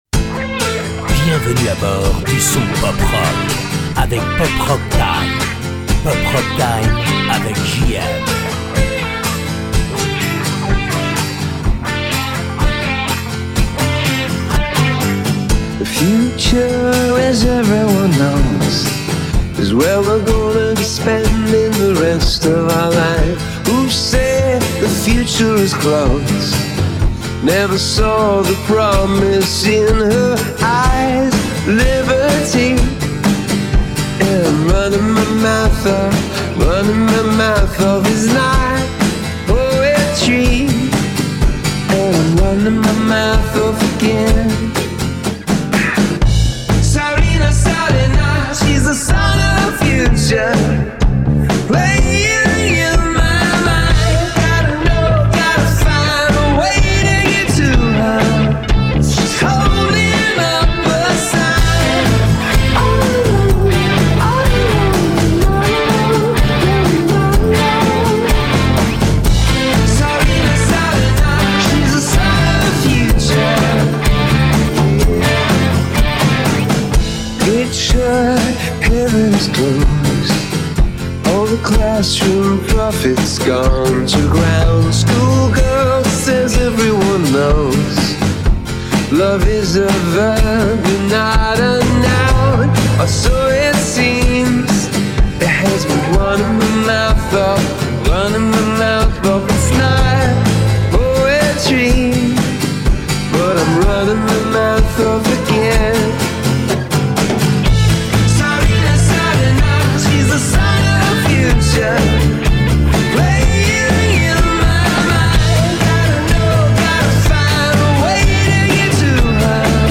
Pour vous maintenant en podcast le magazine de canal30